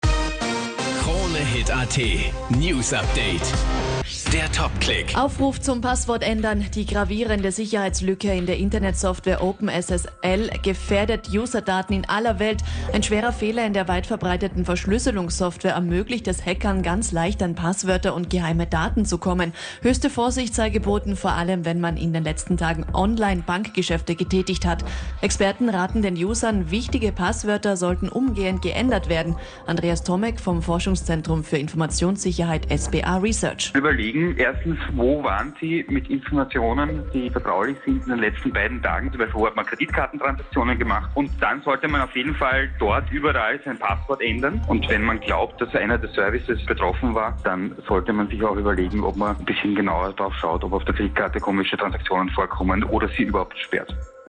Radio-Mitschnitt